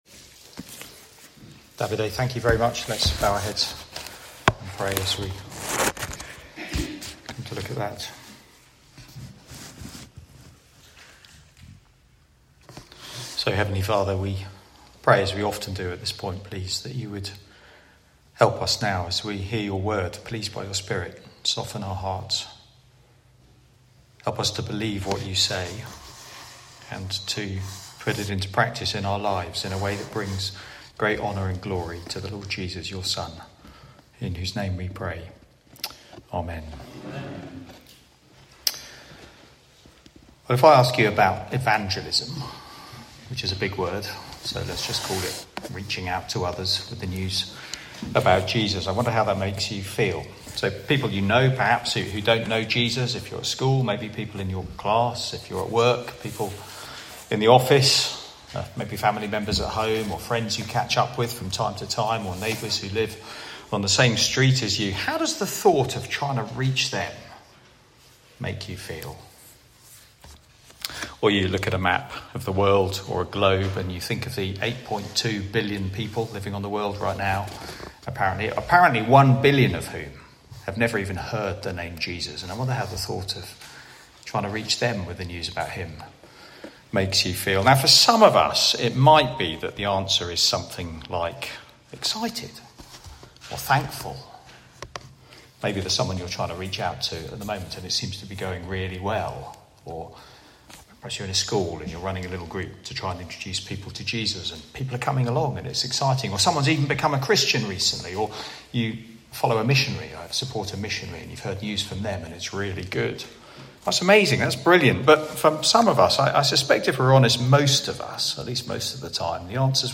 Theme: Sermon